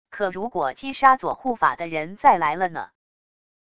可如果击杀左护法的人再来了呢wav音频生成系统WAV Audio Player